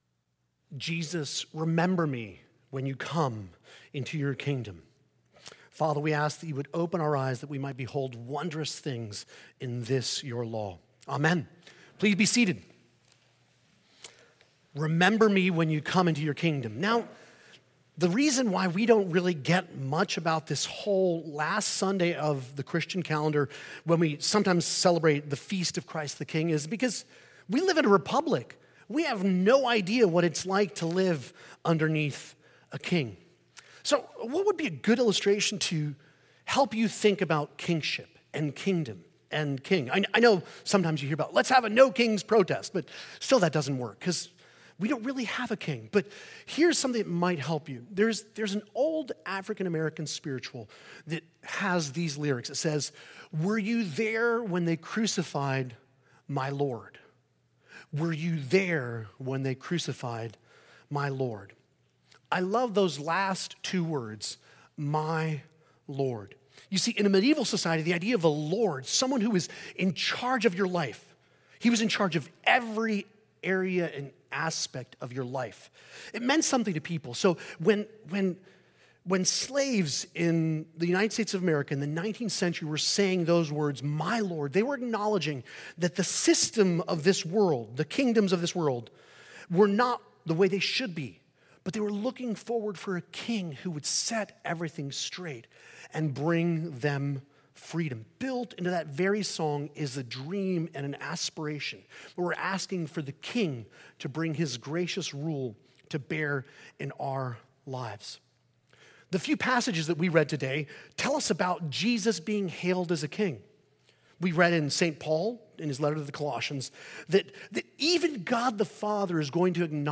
The sermon reflects on Christ the King Sunday , exploring what it means for Jesus to reign as King in a world unfamiliar with monarchy.